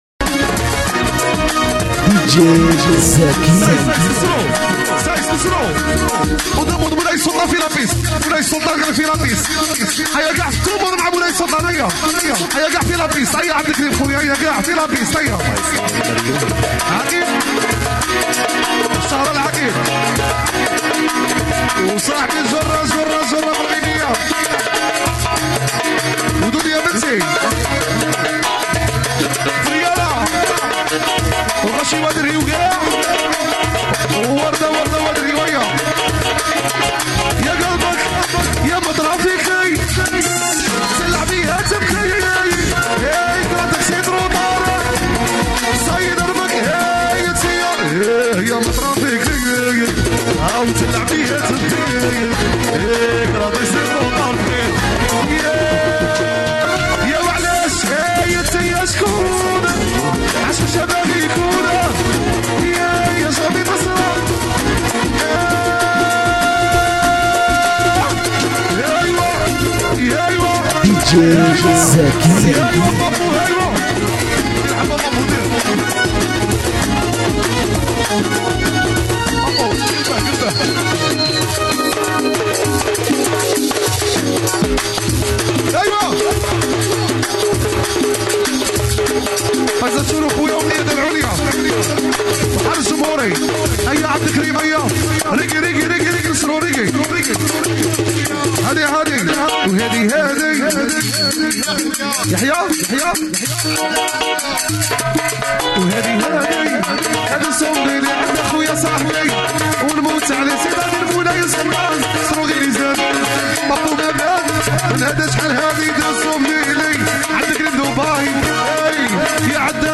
" اغاني راي جزائري "